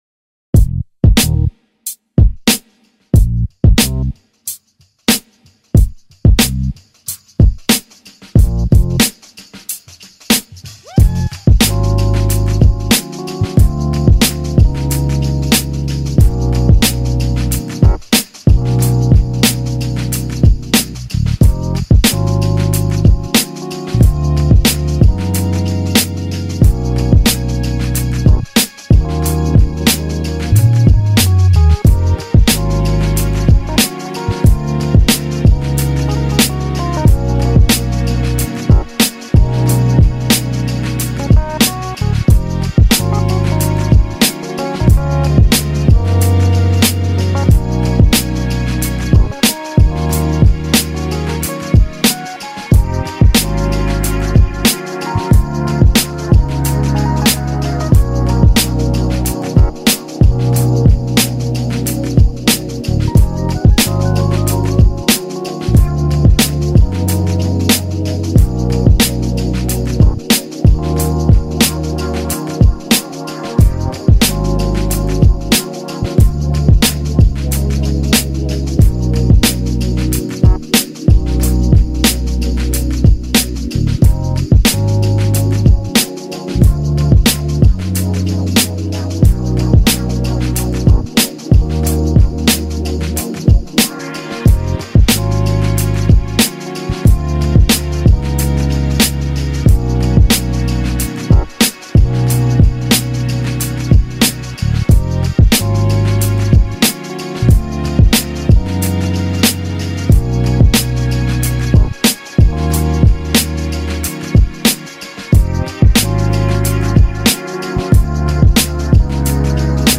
Instrumental (lofi) beats playlists for Spotify